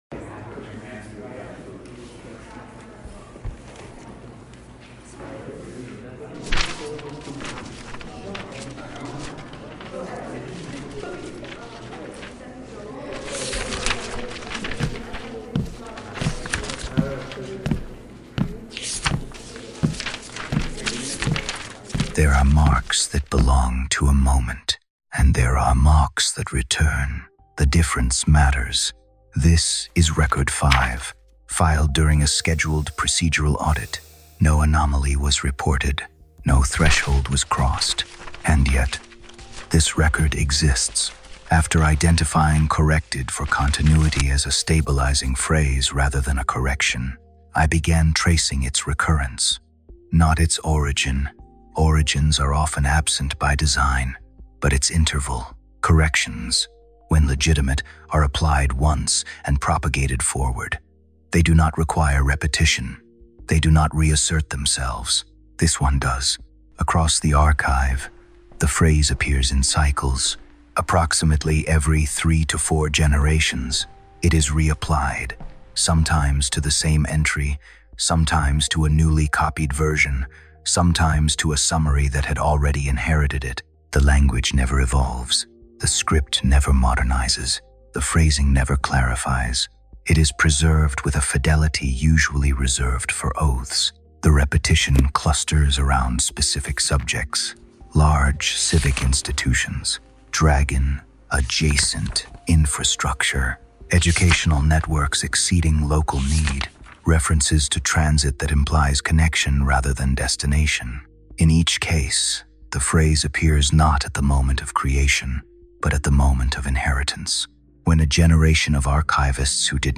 Record 05: The Correction That Repeats – Narration